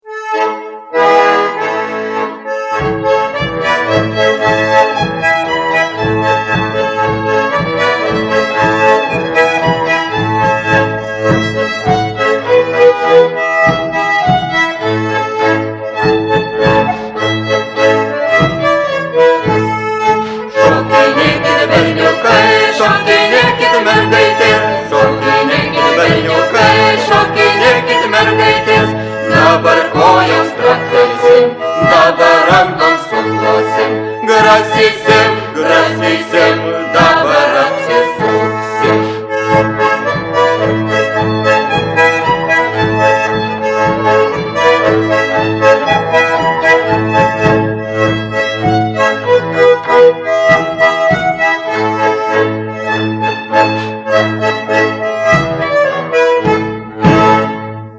Paired dances